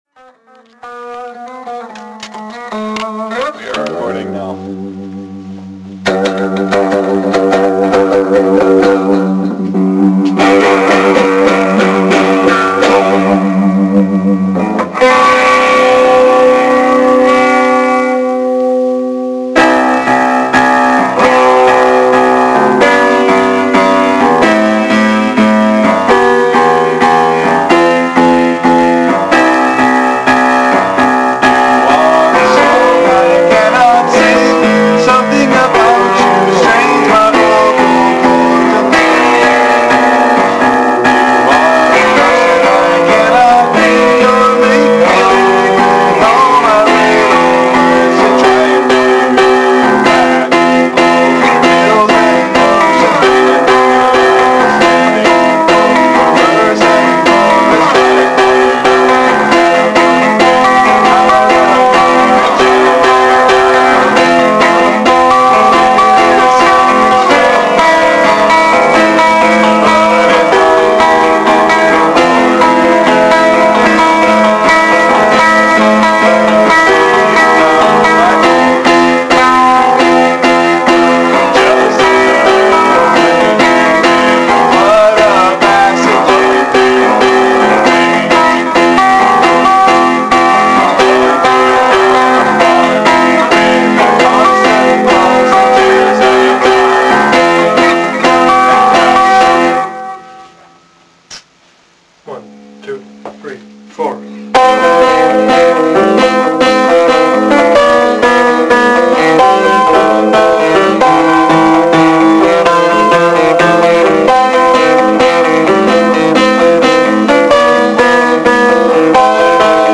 The progression is close to stock (truncated Pachelbel's canon) but in the original meter was intended to have a bluegrass-country feel. Upon playing it together we decided it needed to have a kick in the rhythm, so we used a 7 meter.